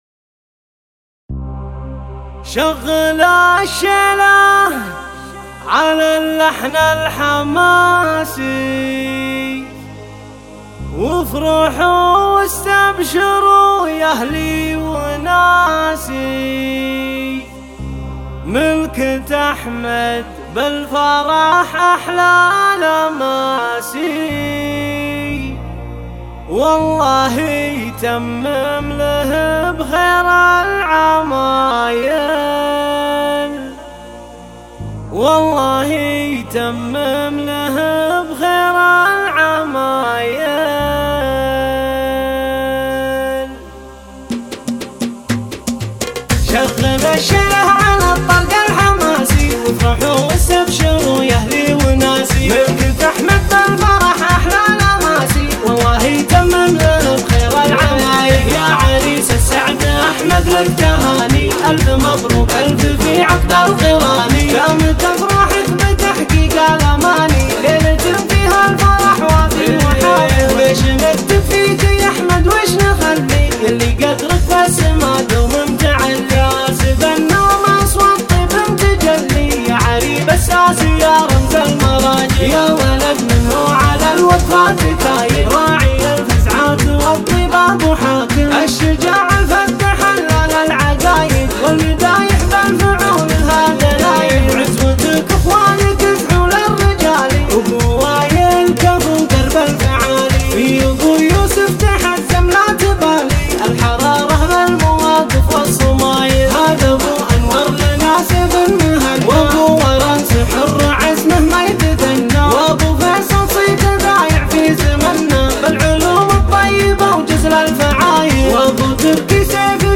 الشيله
اللحن الحماسي